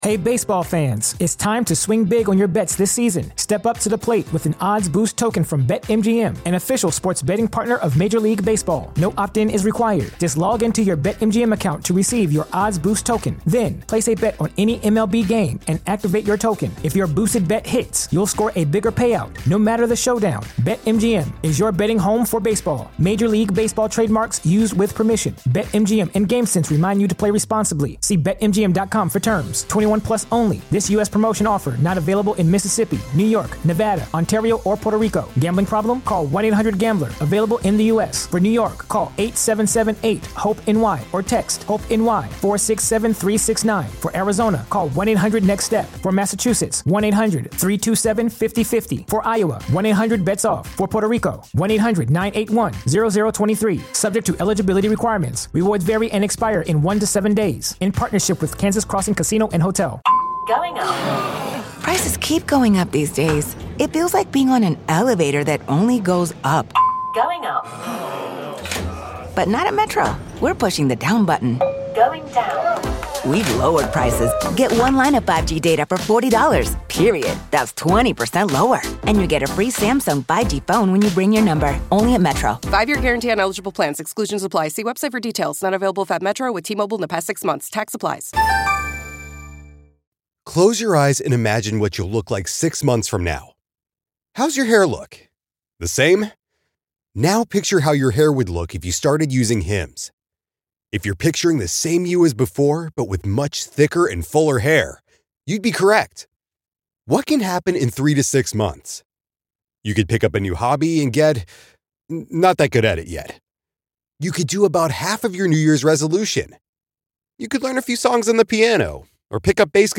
Birdland Tonight is a Baltimore Orioles Postgame Show. Birdland Tonight features a collection of hosts from Baltimore Orioles podcasts and blogs.